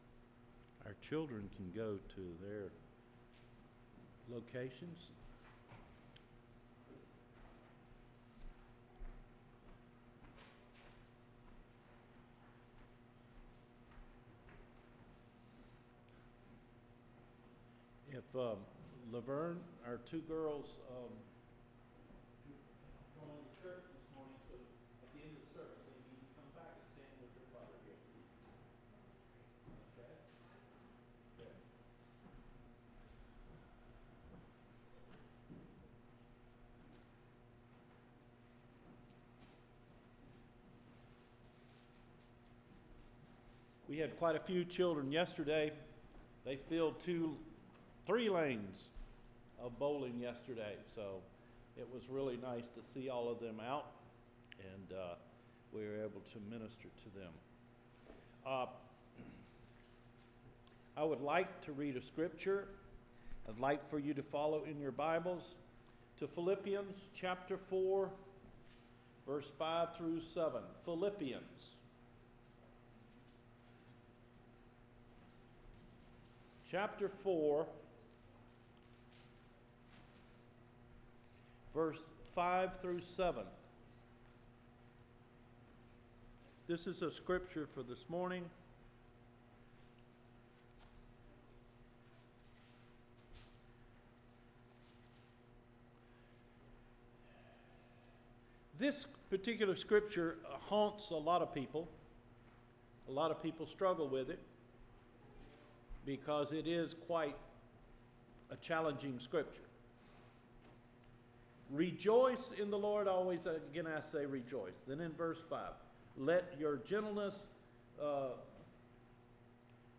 Deliverance From Debilitating Anxiety – AUGUST 18 SERMON